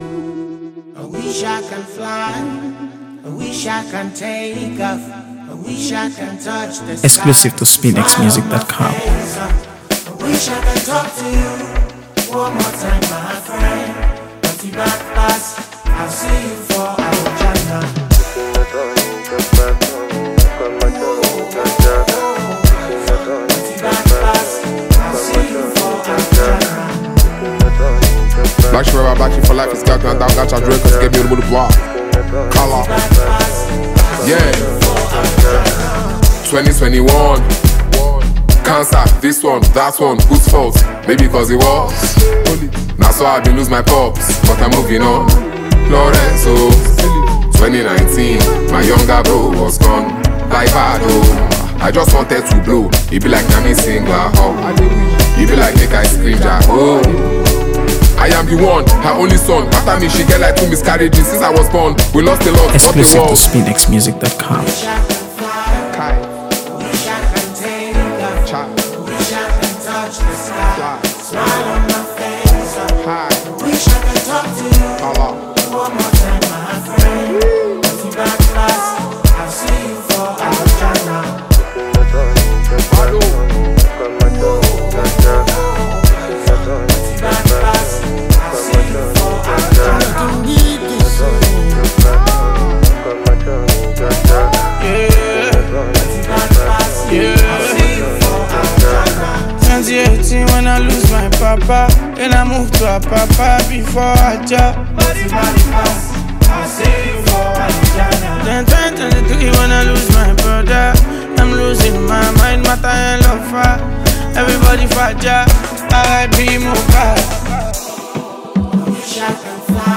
AfroBeats | AfroBeats songs
The song brings together three unique voices
delivers his raw, gritty bars with signature intensity